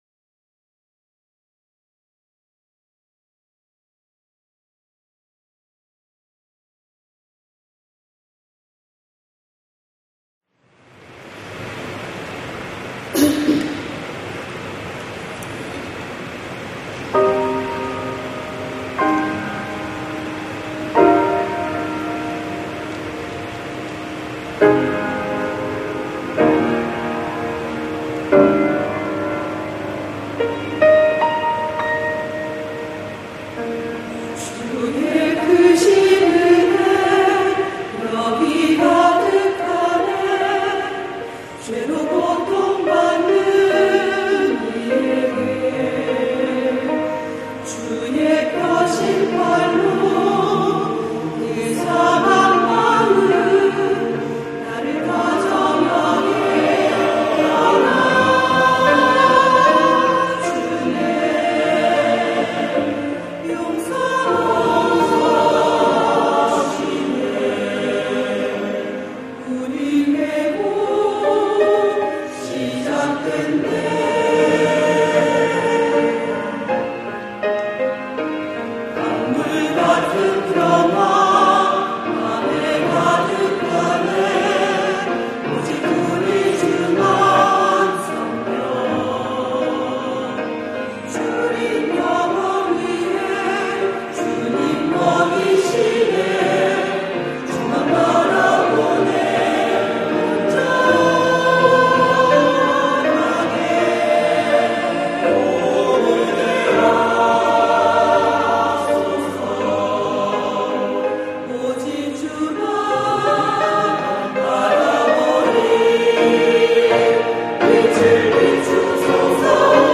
회복의 은혜가 넘치는 곳 > 찬양영상